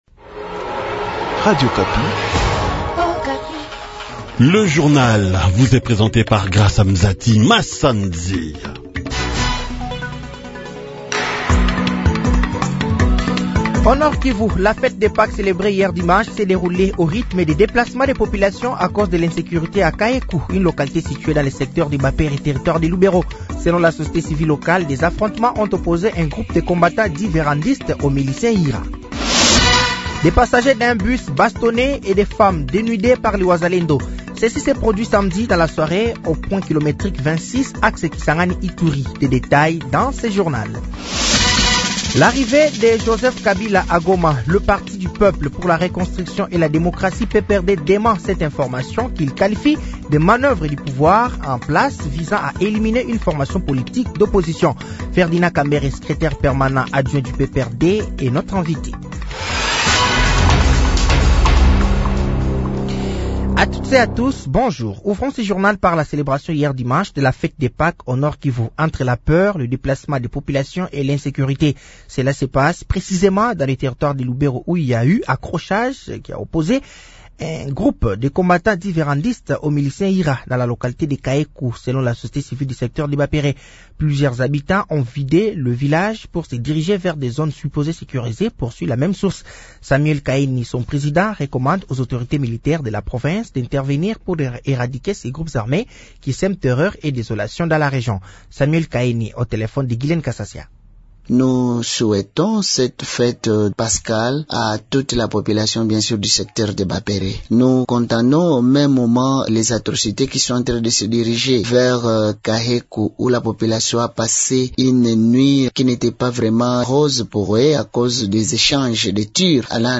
Journal français de 08h de ce lundi 21 avril 2025